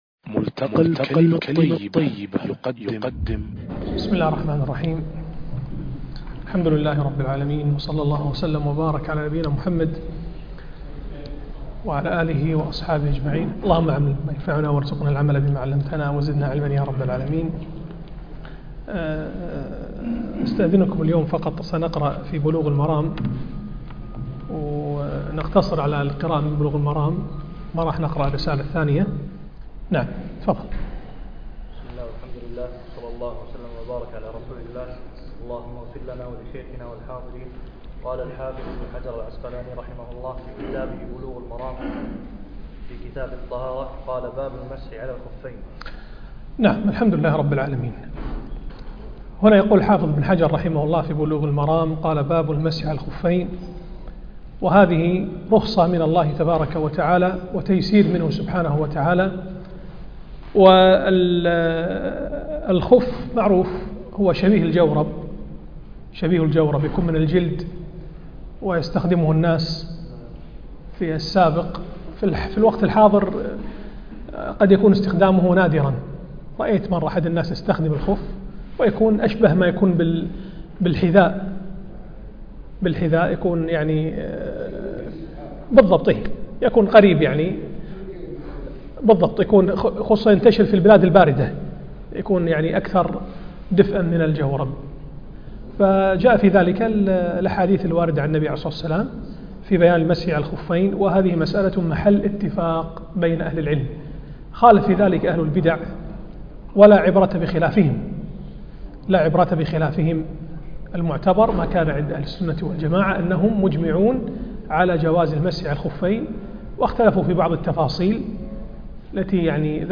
الدرس (8) شرح كتاب بلوغ المرام